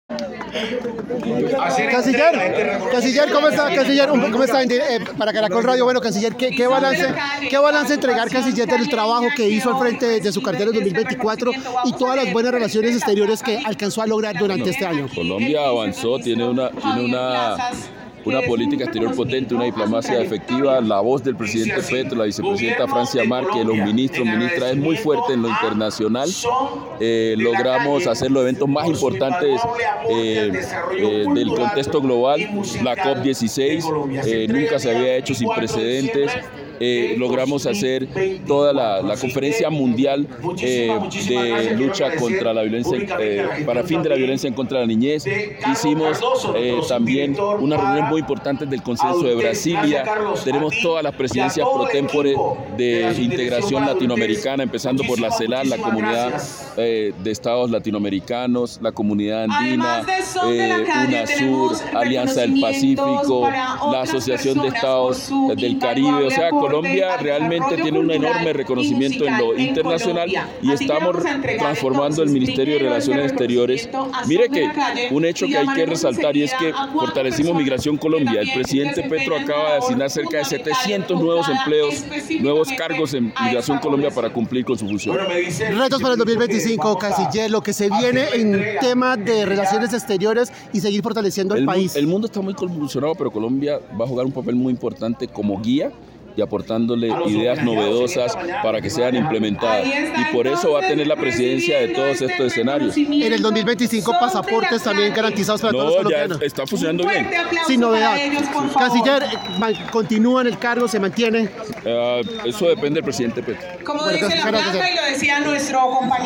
El canciller Luis Gilberto Murillo en dialogo con caracol radio, aseguró que continuará al frente de la cartera de Relaciones Exteriores, hasta que el presidente Gustavo Petro lo decida.